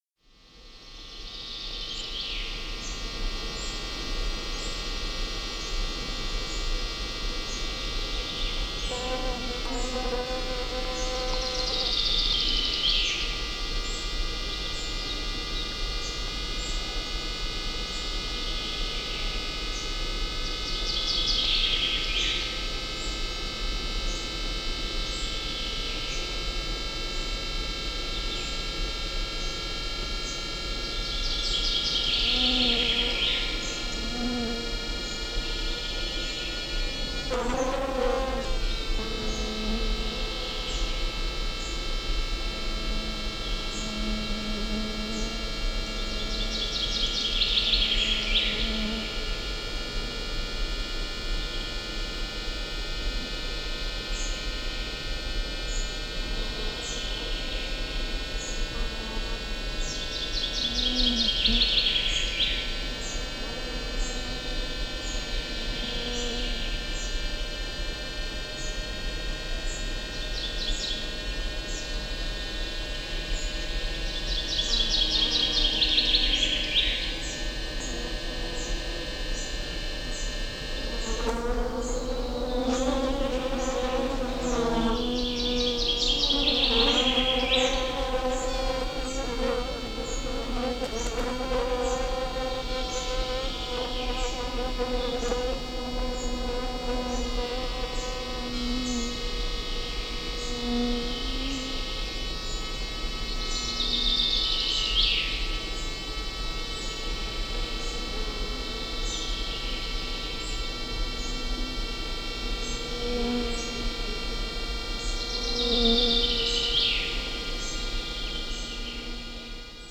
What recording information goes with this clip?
Includes specially-compiled 60 minute CD of field recordings from the Gruenrekorder label.